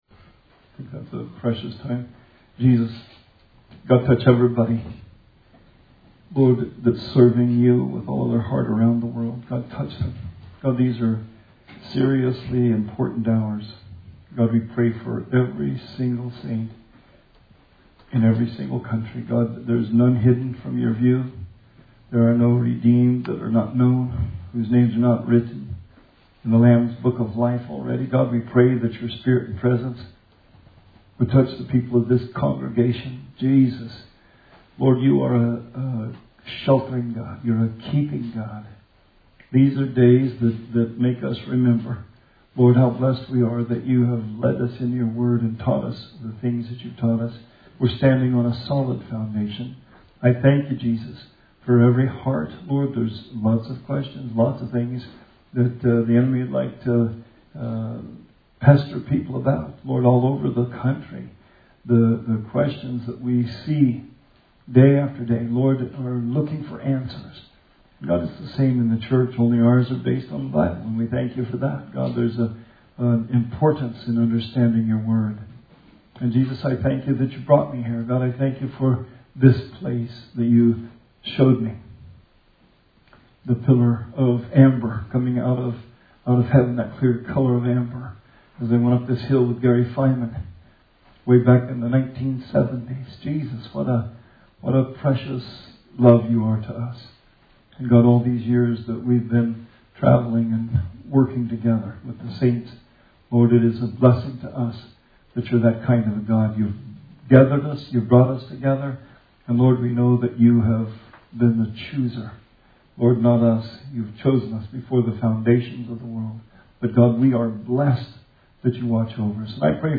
Bible Study 4/15/20